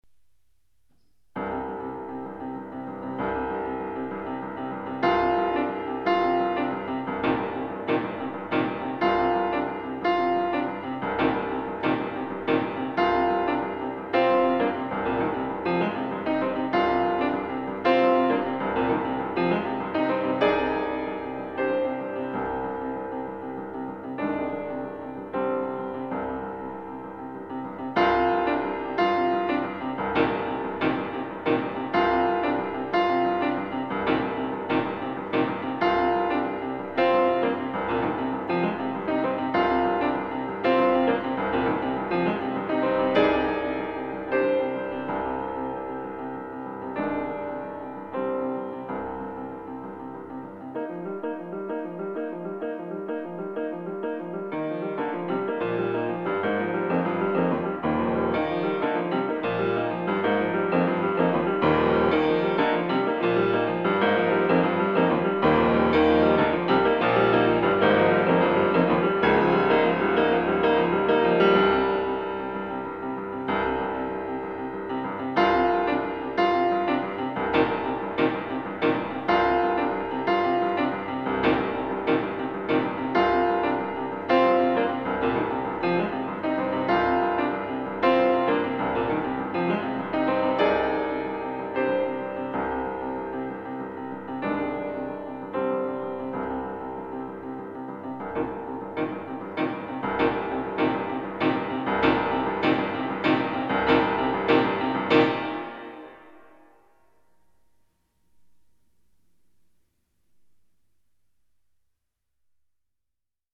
Here's some of my piano recordings.